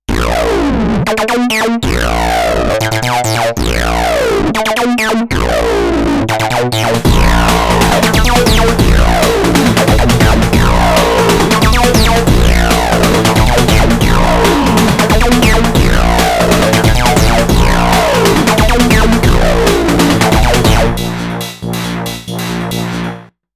Yes, it is somewhat funny to want to put the whole ST into a pedal, since the FX Drive is like a pedal:
ST, no pedal.